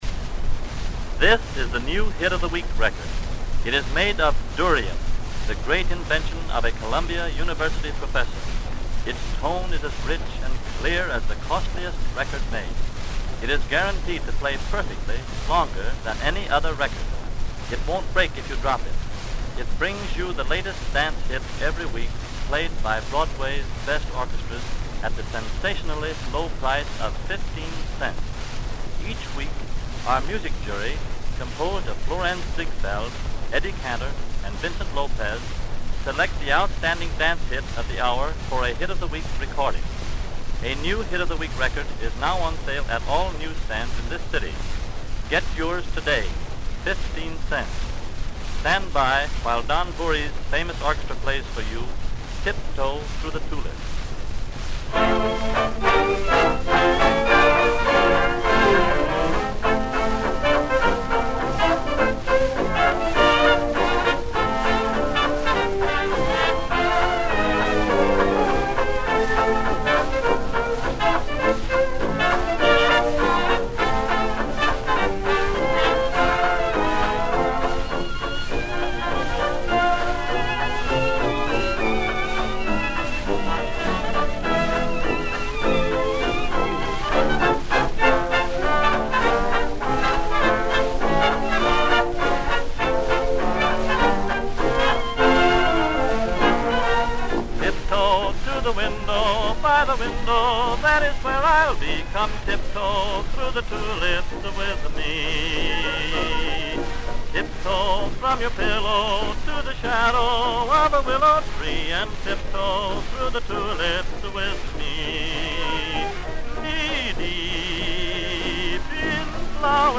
(Announced DEMONSTRATION RECORD)